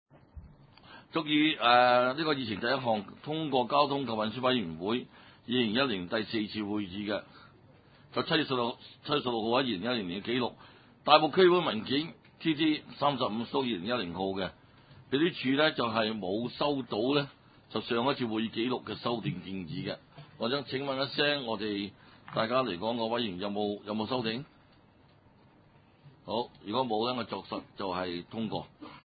交通及運輸委員會2010年第五次會議
地點：大埔區議會秘書處會議室